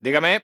Título Hombre contestando al teléfono: ¡Dígame!